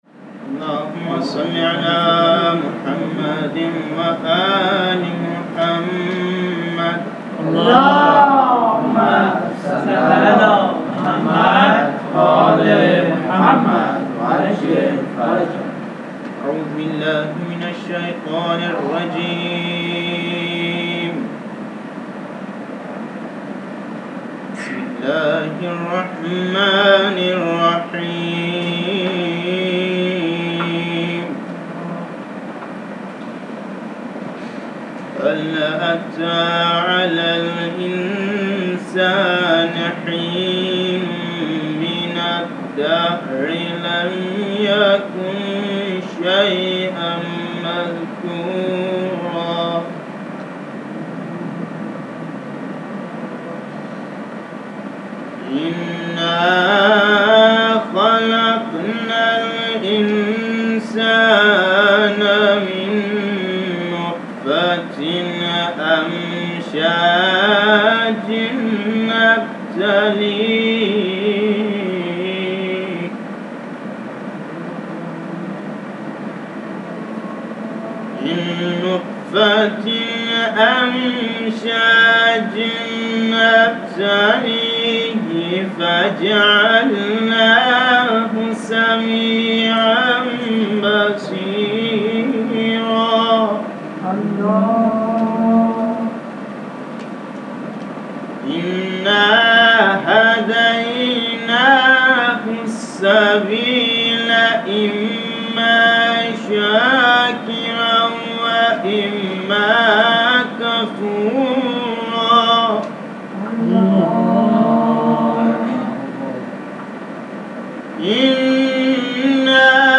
صوت دلنشین قرآن سوره انسان